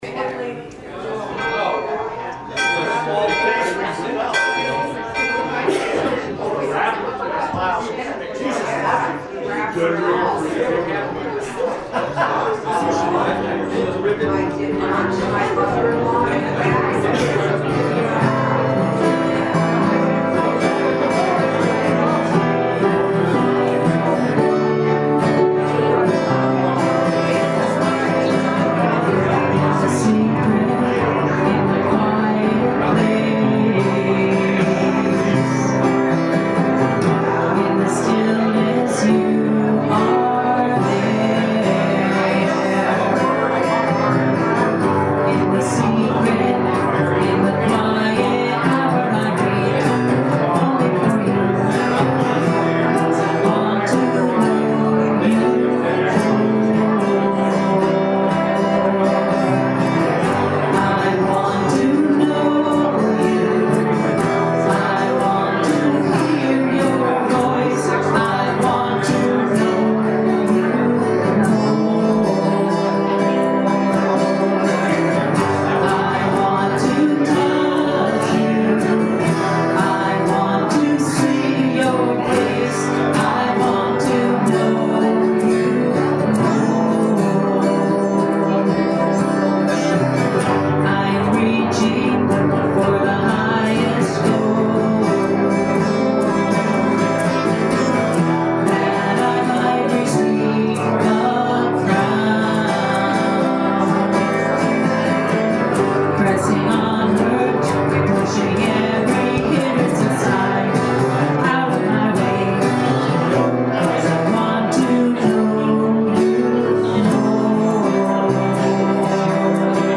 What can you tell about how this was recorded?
April 24th, 2016 Service Podcast Prelude: In the Secret